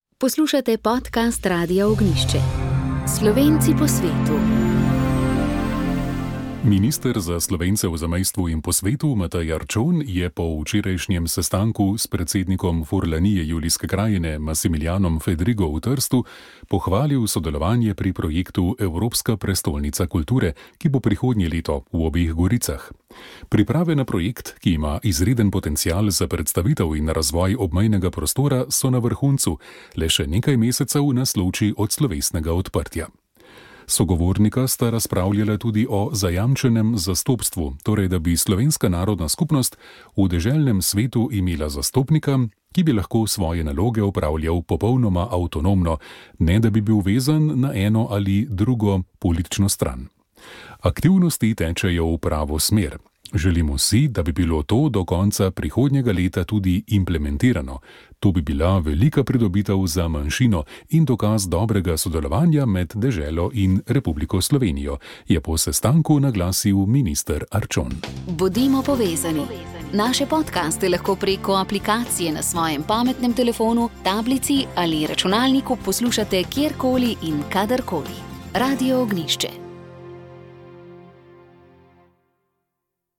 v drugem delu pa ste slišali nadaljevanje pogovora